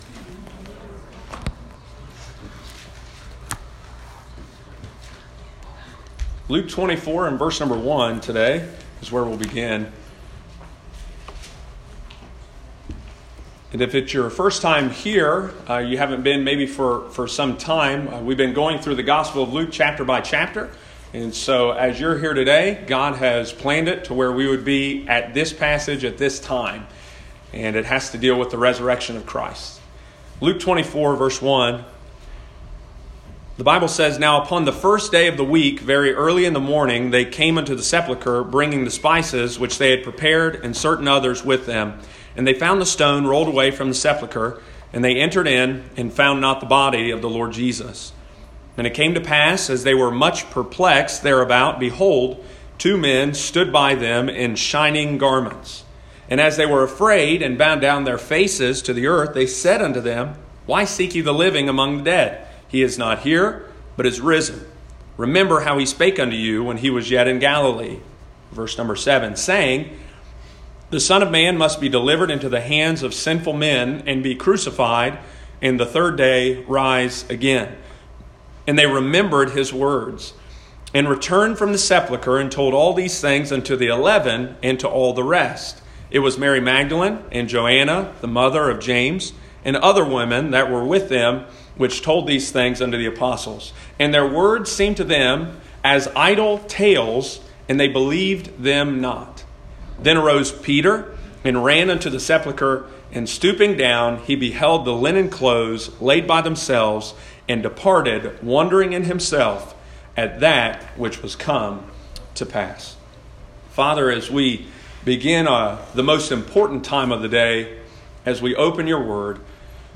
continues the Gospel of Luke series on Fall Family Day 2019